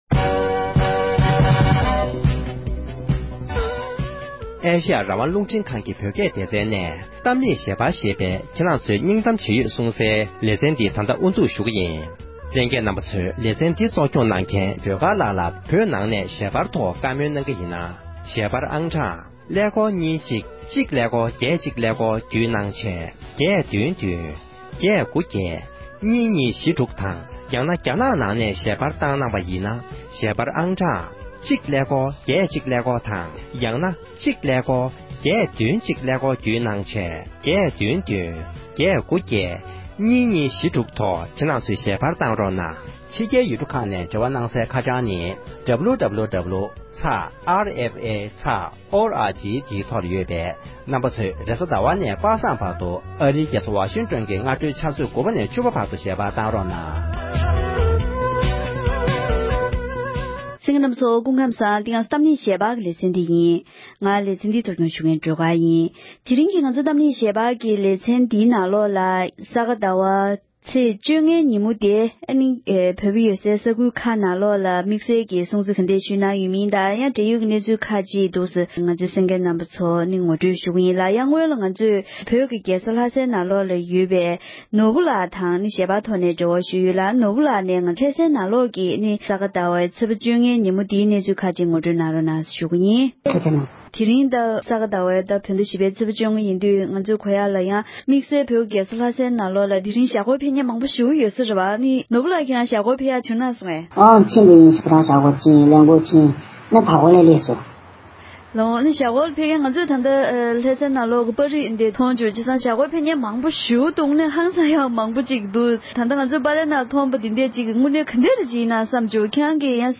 འབྲེལ་ཡོད་མི་སྣ་དང་བཀའ་མོལ་ཞུས་པར་གསན་རོགས་ཞུ༎